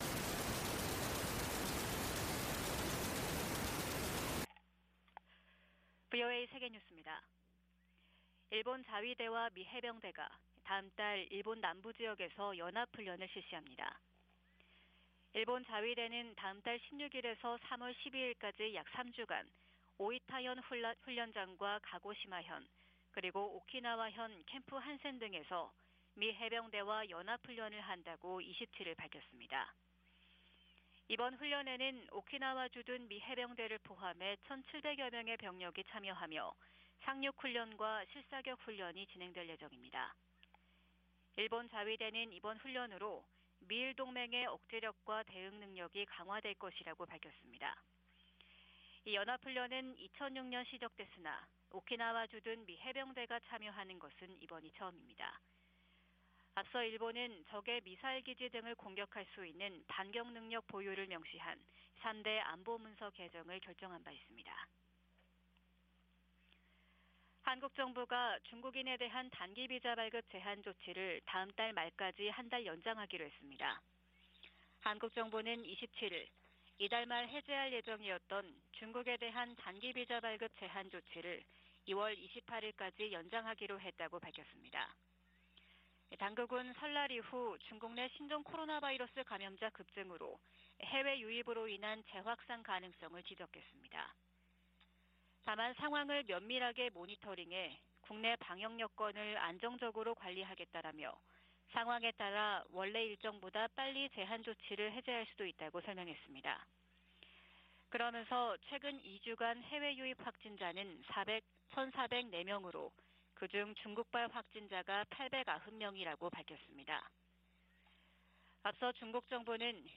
VOA 한국어 '출발 뉴스 쇼', 2023년 1월 28일 방송입니다. 미국과 한국의 국방장관들이 31일 서울에서 회담을 갖고 대북정책 공조, 미국 확장억제 실행력 강화 등 다양한 동맹 현안들을 논의합니다. 미국 정부가 러시아 군사조직 바그너 그룹을 국제 범죄조직으로 지목하고 현행 제재를 강화했습니다.